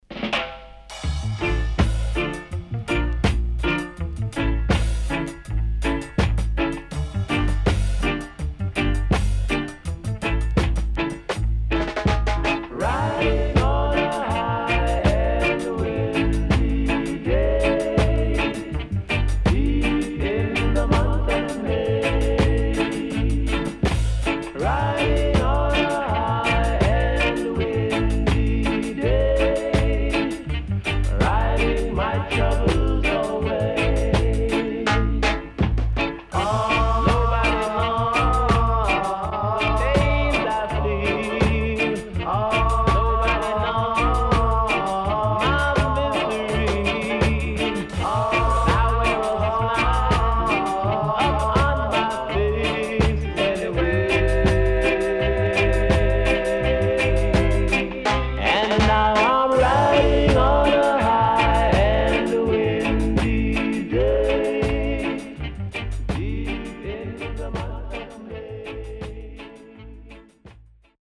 SIDE A:少しチリノイズ、プチノイズ入りますが良好です。